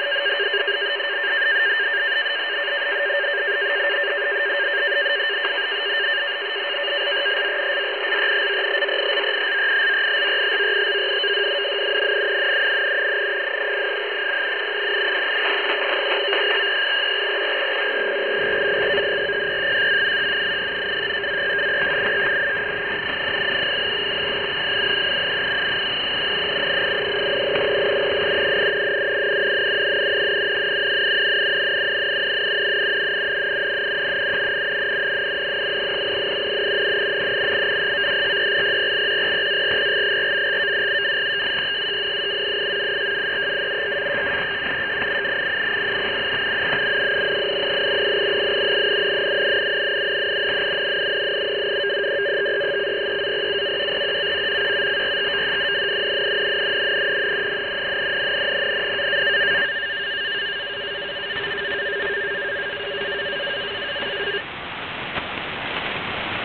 Начало » Записи » Радиоcигналы на опознание и анализ
SITOR-B в трехканальном режиме